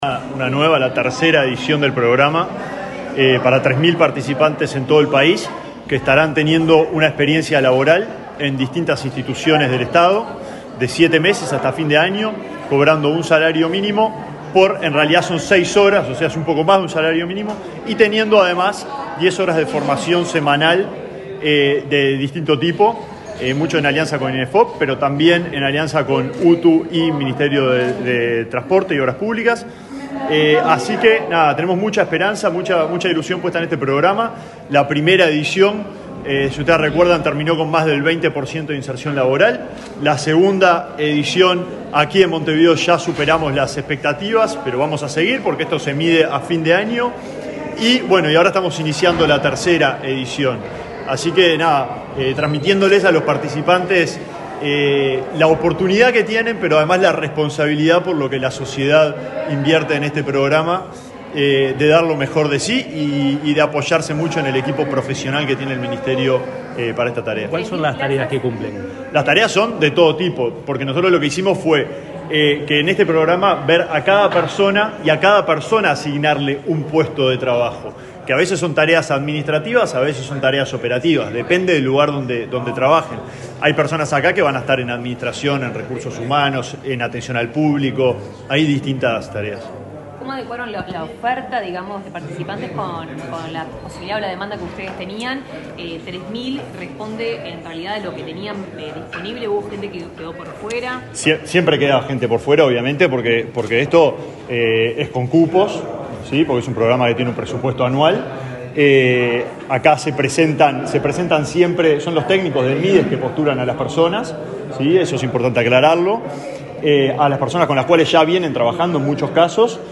Declaraciones del ministro de Desarrollo Social, Alejandro Sciarra
El ministro de Desarrollo Social, Alejandro Sciarra, dialogó con la prensa en Montevideo, luego de participar en el lanzamiento de la edición 2024 del